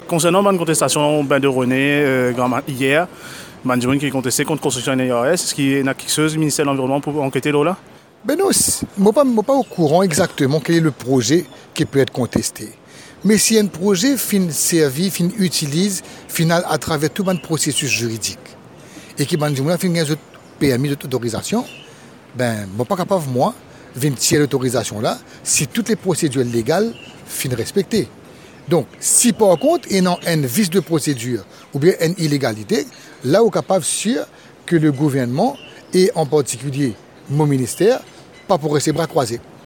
Il était interrogé sur la manifestation organisée sur la plage de Bain-des-Rosnay, dimanche 17 septembre, contre la construction prochaine d’appartements de luxe à proximité de la plage. Le ministre de l’Environnement et de la sécurité sociale intervenait à l’issue d’un atelier de travail sur la signature du traité de Kigali sur la protection de l’environnement et de la couche d’ozone.